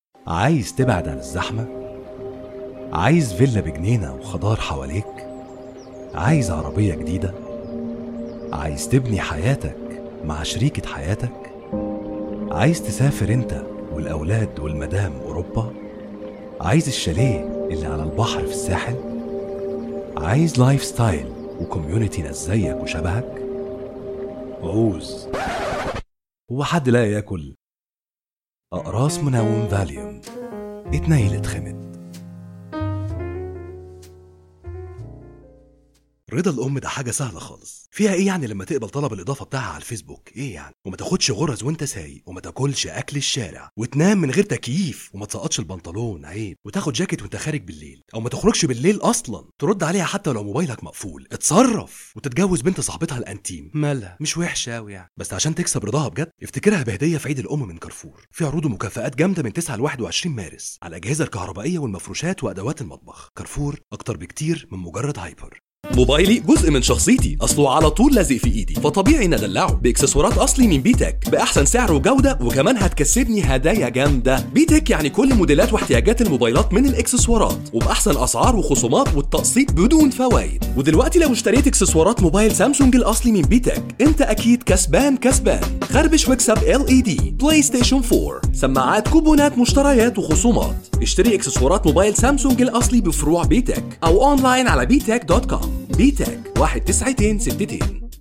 Mısır Arapçası Seslendirme
Erkek Ses